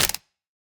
Minecraft Version Minecraft Version snapshot Latest Release | Latest Snapshot snapshot / assets / minecraft / sounds / block / mangrove_roots / step2.ogg Compare With Compare With Latest Release | Latest Snapshot
step2.ogg